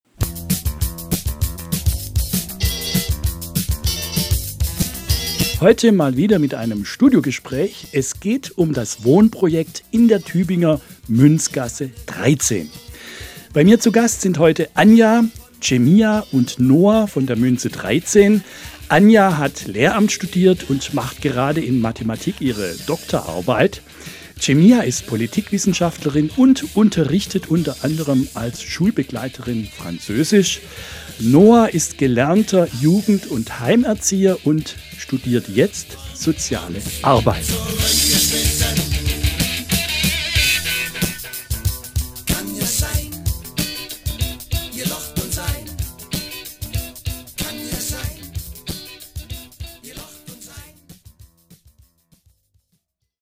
Hier kommt ein spannendes und informatives Studiogespräch mit Bewohner*innen des Wohnprojekts Münze 13, über Wohnungsnot und alternatives Wohnen.